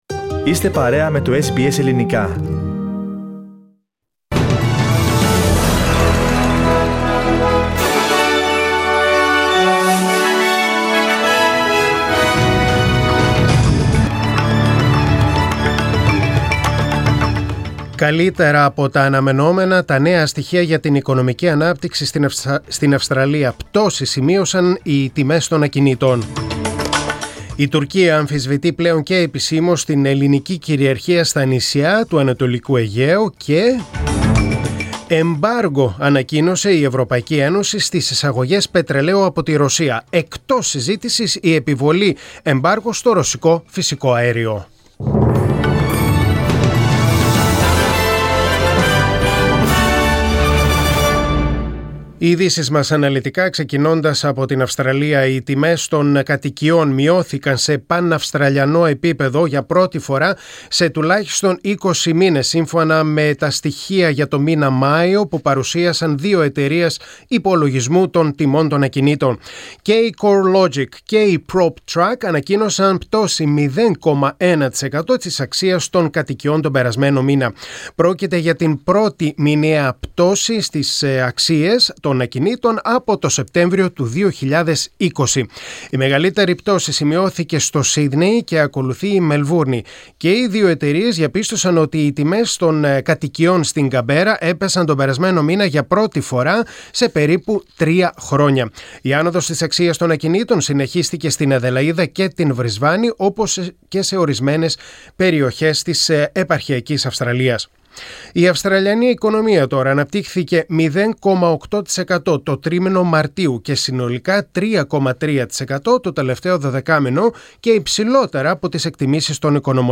Δελτίο Ειδήσεων: Τετάρτη 1.6.2022